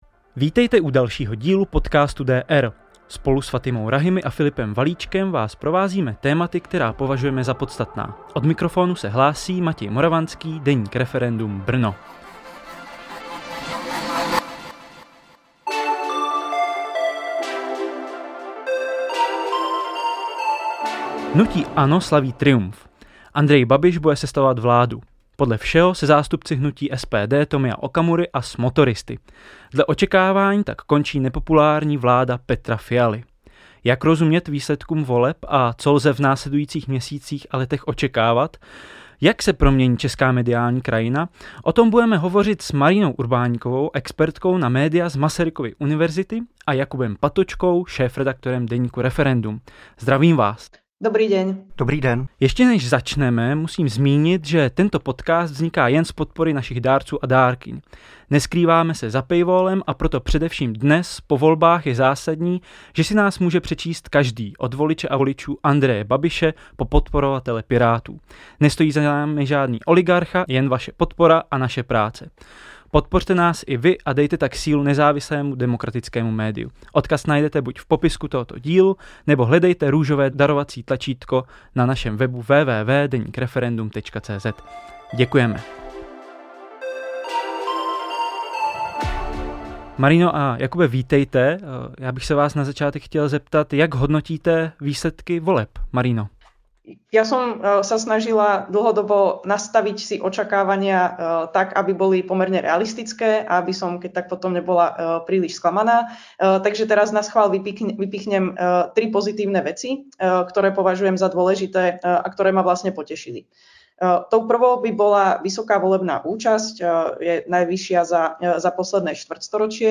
Hovořili jsme s expertkou na média